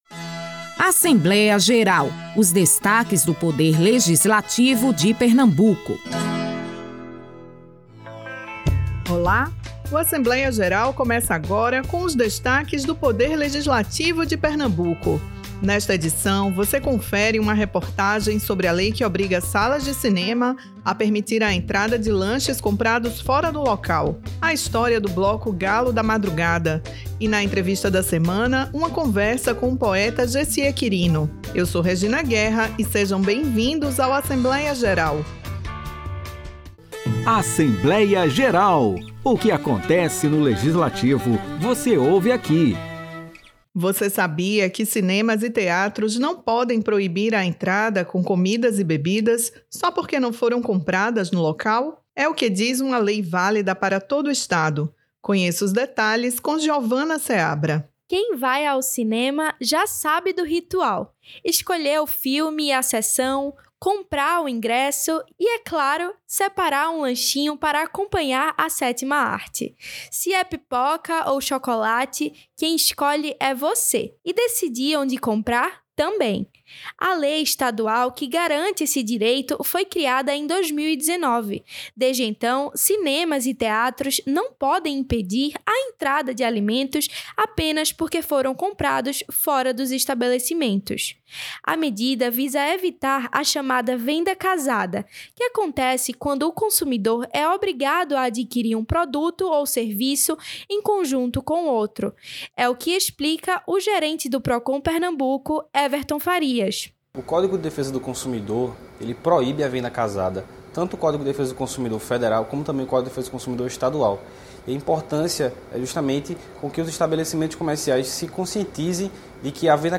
Galo da Madrugada e entrevista com Jessier Quirino em destaque no Assembleia Geral
O programa Assembleia Geral é uma produção semanal da Rádio Alepe, com os destaques do Legislativo pernambucano.